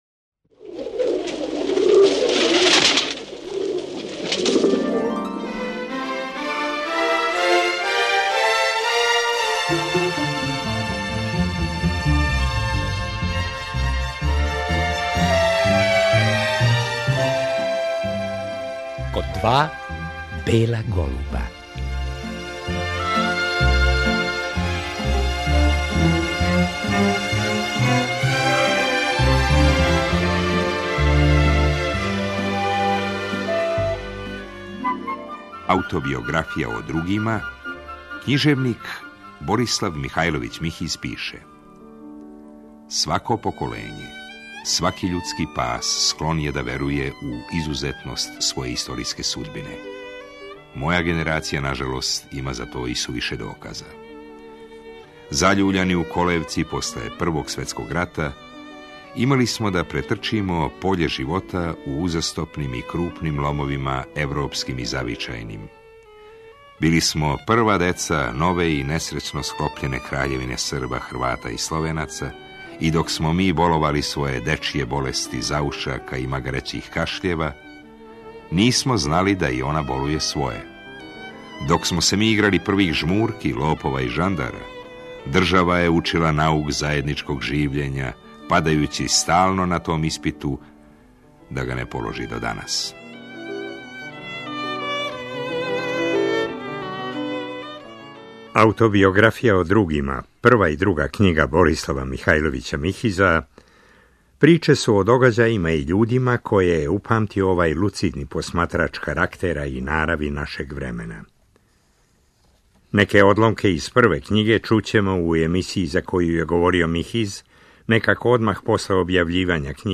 Гост ове емисије био је 1990. године некако одмах по објављивању књиге „Аутобиографија о другима" I део.